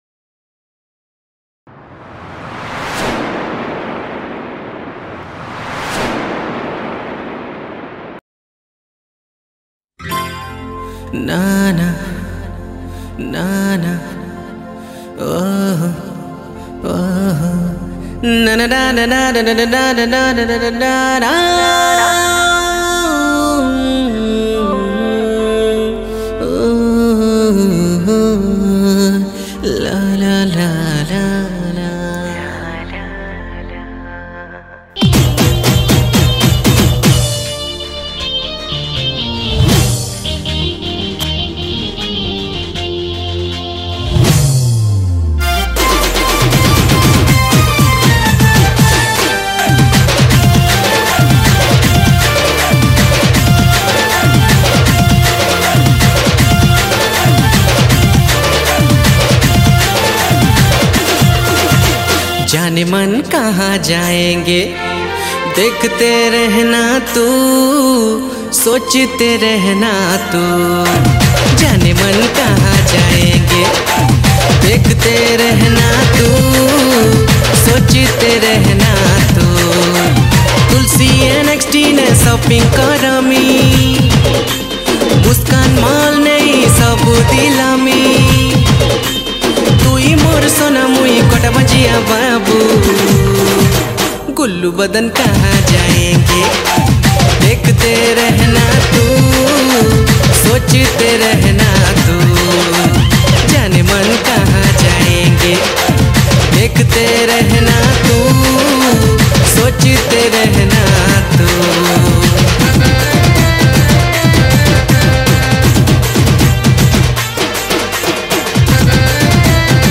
Sambalpuri Love Romatic Song HD Studio Version